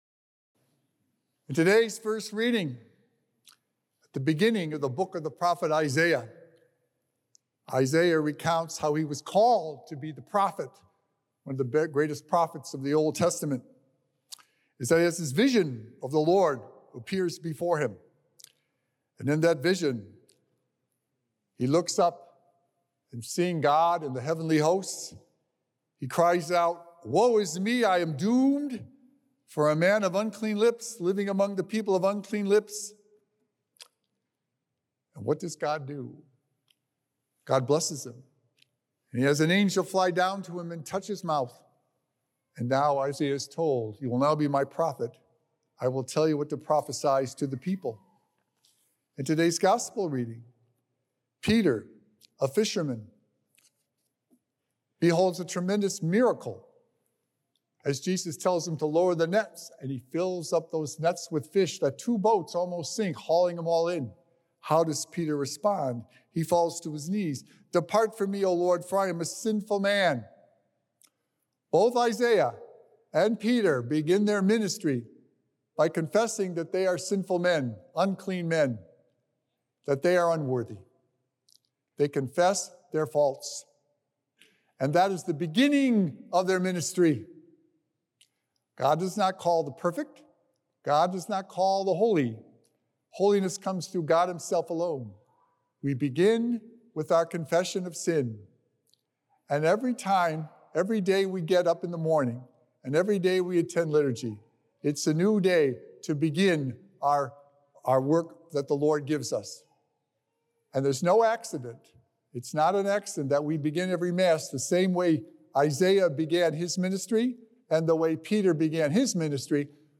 Recorded Live on Sunday, February 9th, 2025 at St. Malachy Catholic Church.